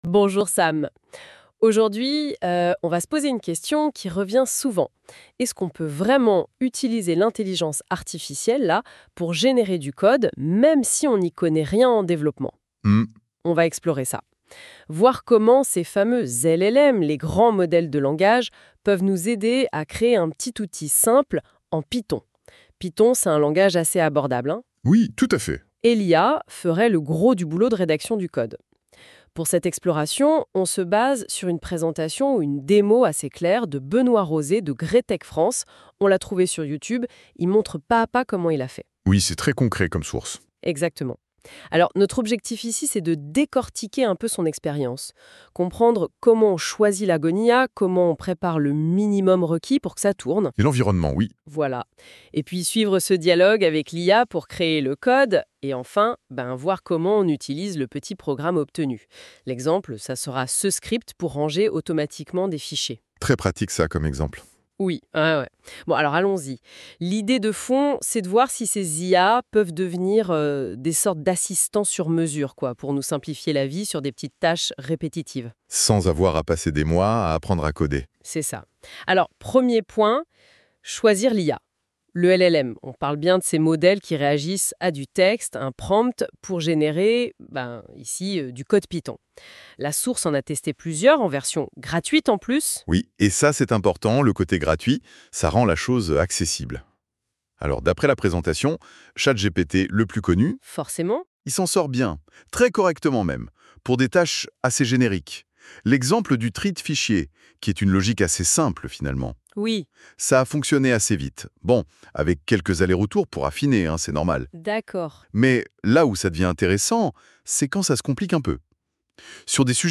Présentation vidéo de GRAITEC France